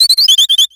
Cries
FLETCHLING.ogg